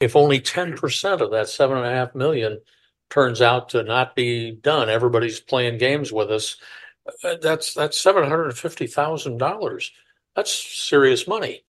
Commissioner John Gisler says he is concerned.